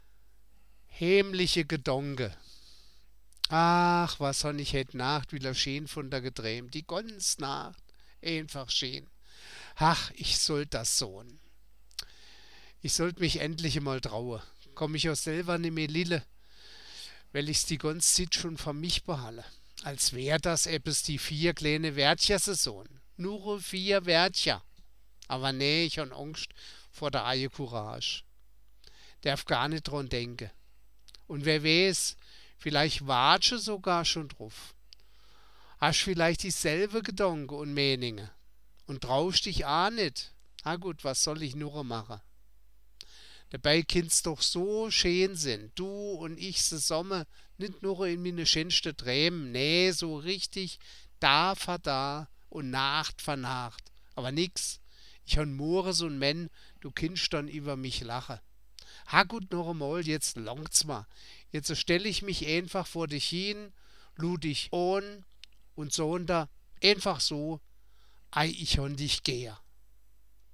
4.5 Mundartgedichte und -geschichten auf Ensemma Pladd